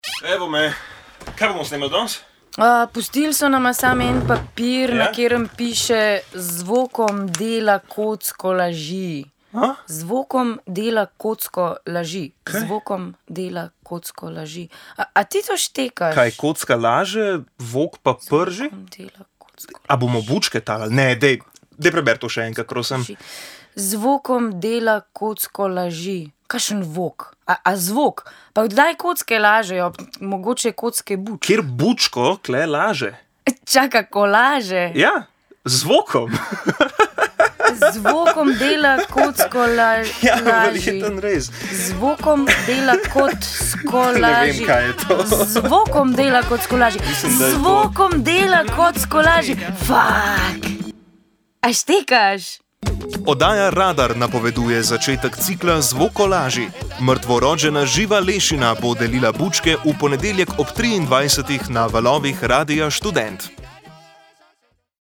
En zvokolaž